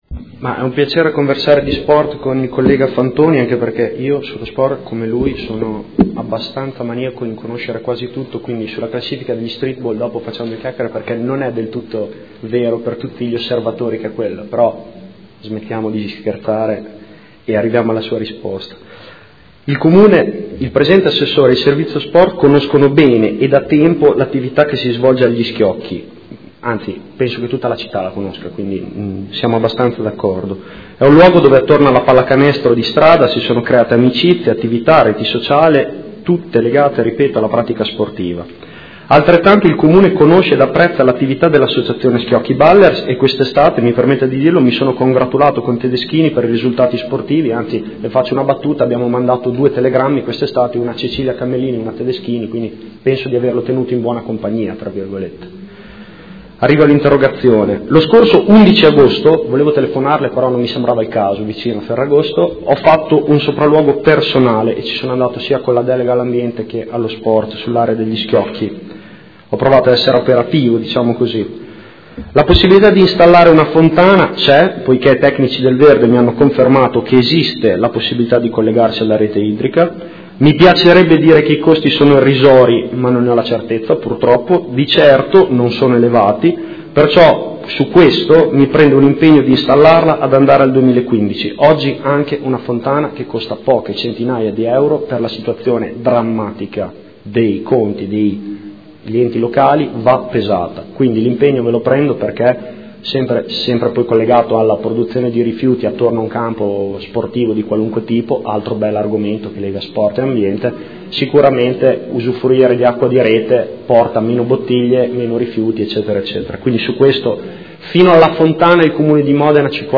Seduta del 18 settembre. Interrogazione del consigliere Fantoni (M5S) avente per oggetto l'assetto dell'area "Campo Schiocchi".